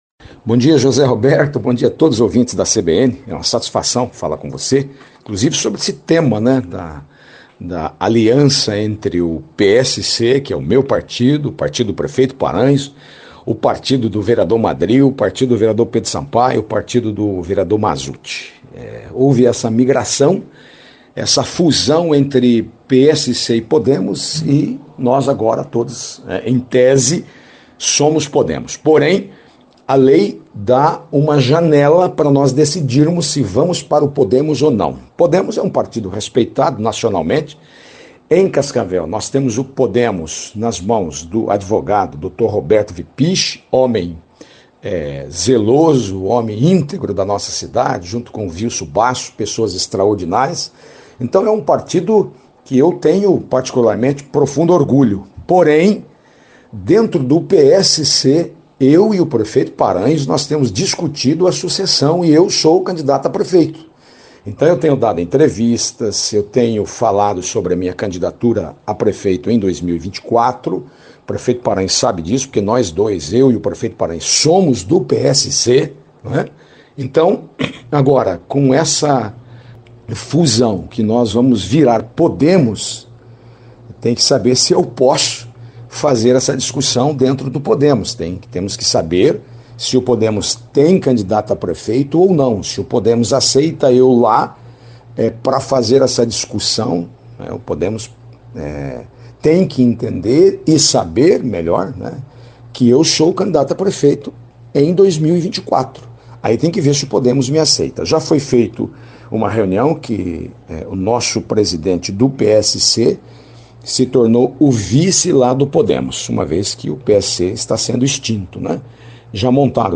Em entrevista à CBN Cascavel nesta quarta-feira (04) o presidente da Câmara de Vereadores, Alécio Espínola, disse que a fusão entre PSC e Podemos o coloca, naturalmente, no Podemos.
Player Ouça Alécio Espinola, presidente da Câmara